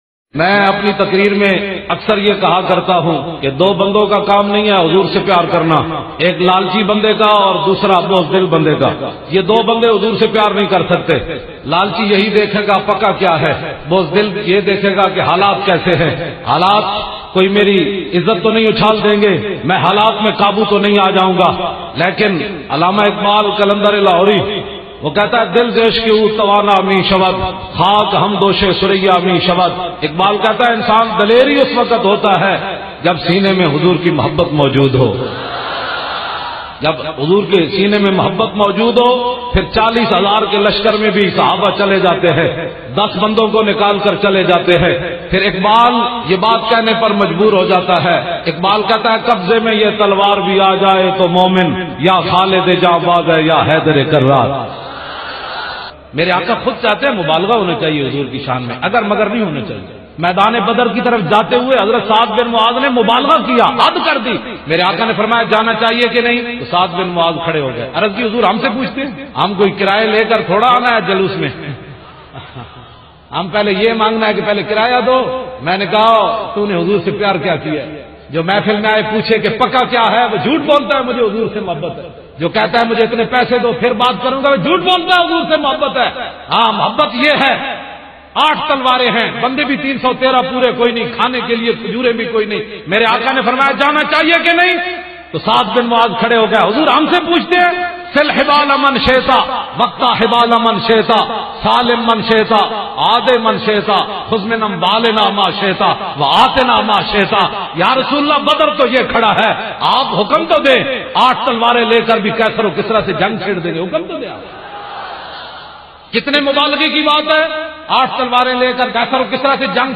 This Short Clip Speech of Allama Khadim Hussain Razavi
speech-clip-of-allama-khadim-hussain-razavi.mp3